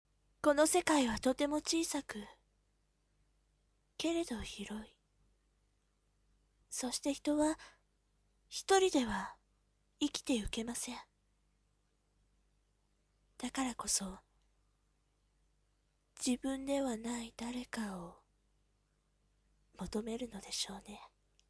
若い女性〜自分ではない誰かを求める訳〜